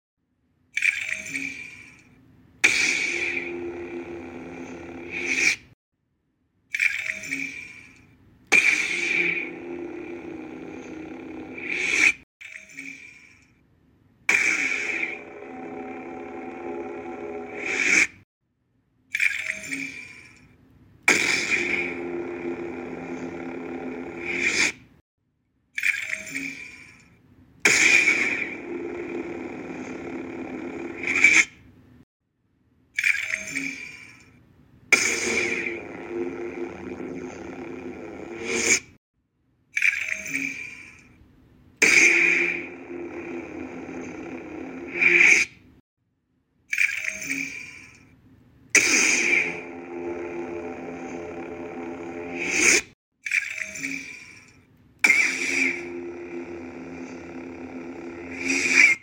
Jedi fallen order all saber sound effects free download
Jedi fallen order all saber colors.